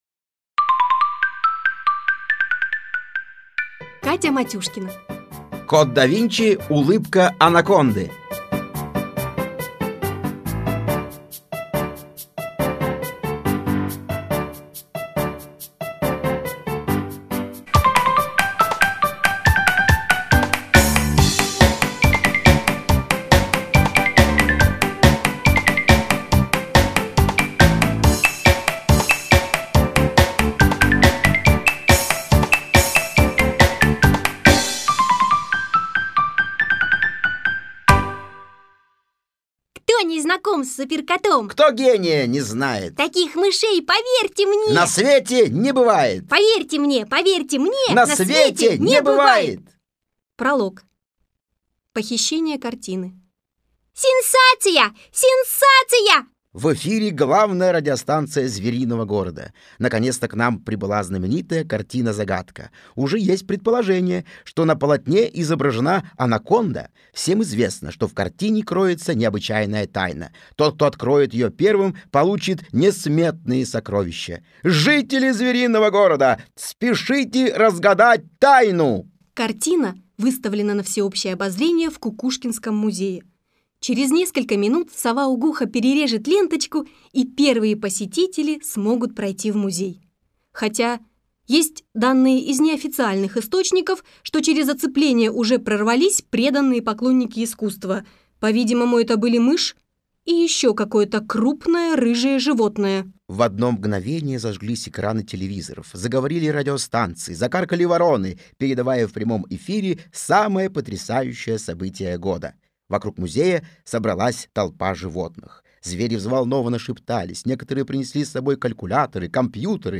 Aудиокнига Кот да Винчи. Улыбка Анаконды